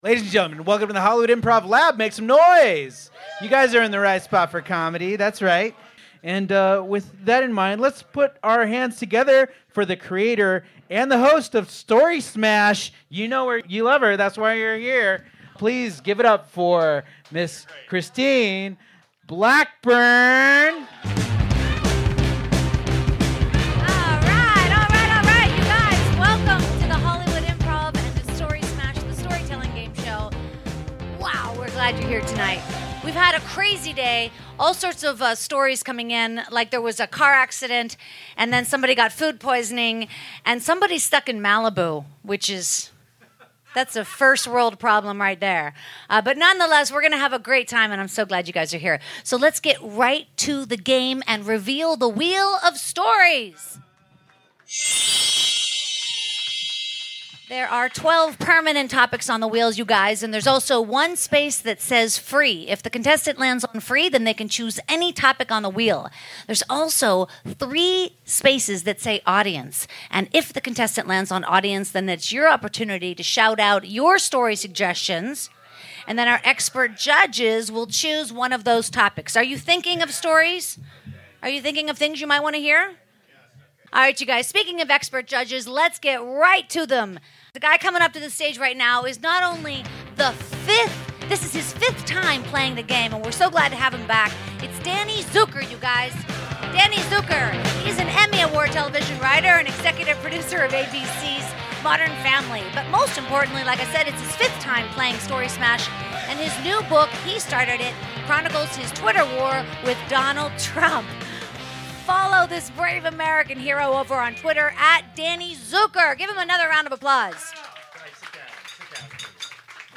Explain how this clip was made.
Story Smash the Storytelling Gameshow LIVE at The Hollywood Improv on May 26th 2018